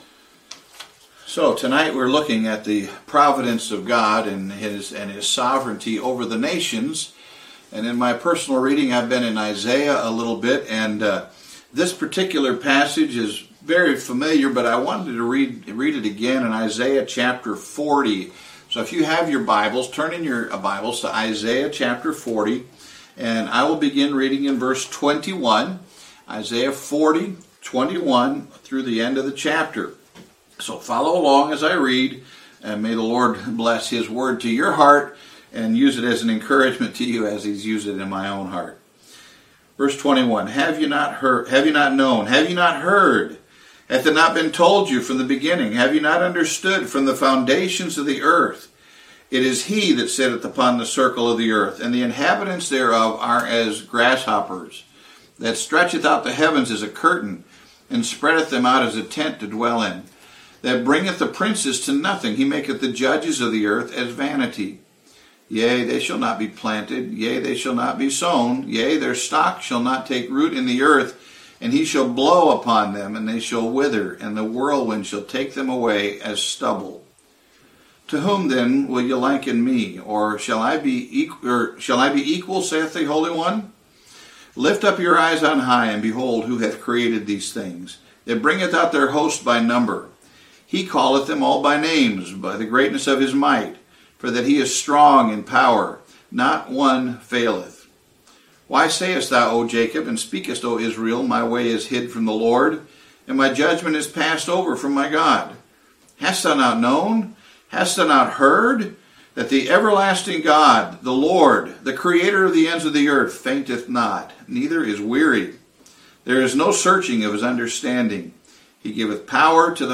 Sermon or written equivalent